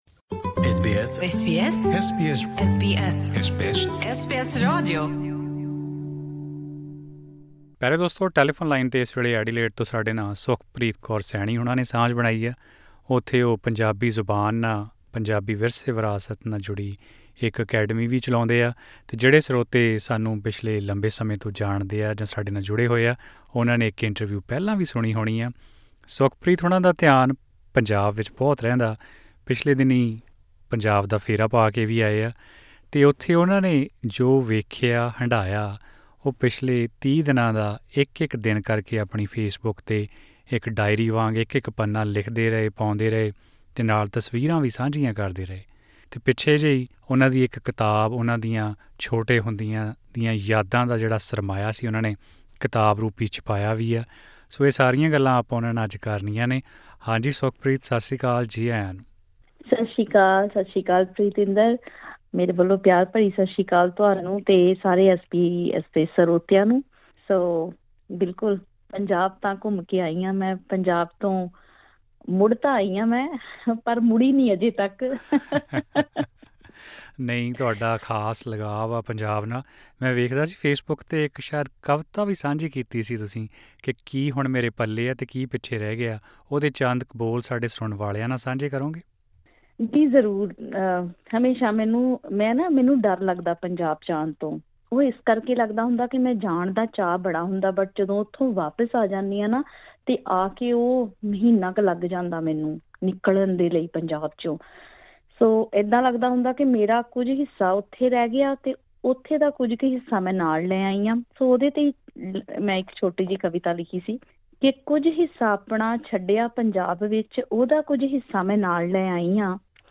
ਹੋਰ ਵੇਰਵੇ ਲਈ ਇਹ ਇੰਟਰਵਿਊ ਸੁਣੋ..........